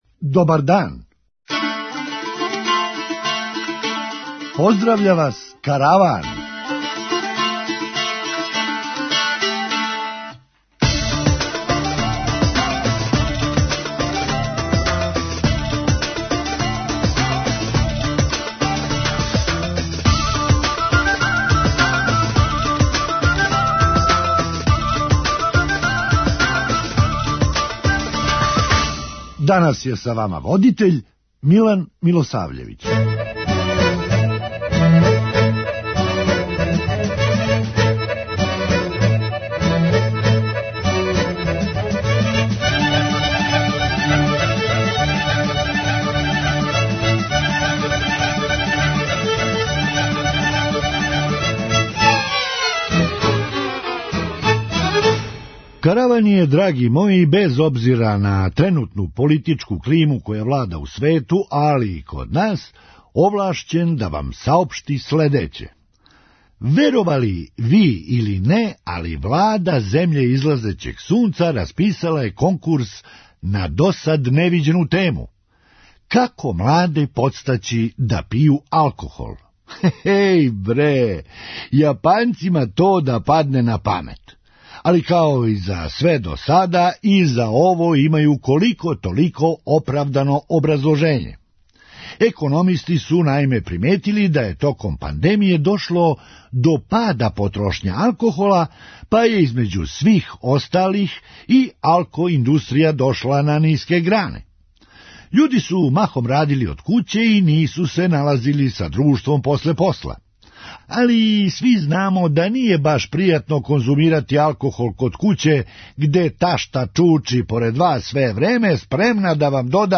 Хумористичка емисија
Што ће рећи, наш најсигурнији прилив из иностранства је сведен на минимум. преузми : 9.17 MB Караван Autor: Забавна редакција Радио Бeограда 1 Караван се креће ка својој дестинацији већ више од 50 година, увек добро натоварен актуелним хумором и изворним народним песмама.